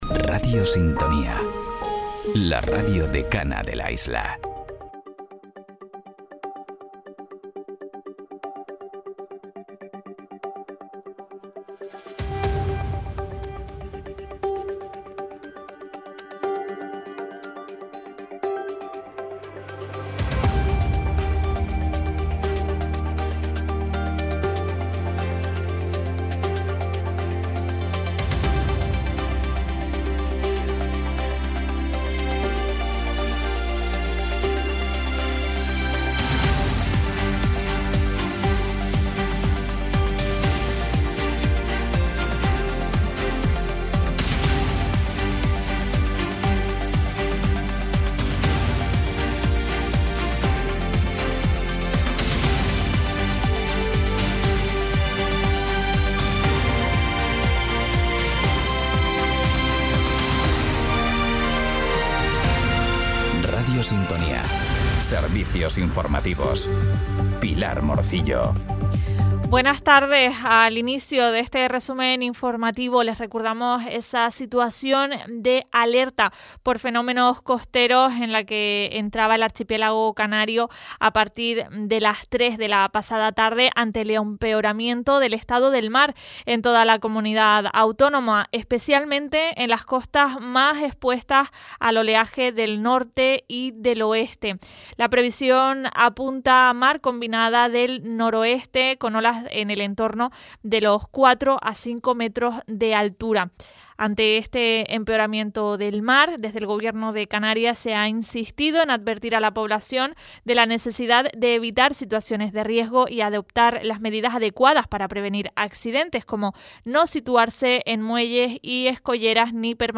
Cada día, desde Radio Sintonía, puedes seguir toda la información local y regional en nuestro espacio informativo. En él te contamos, en directo, las noticias más importantes de la jornada, a partir de las 13:15h.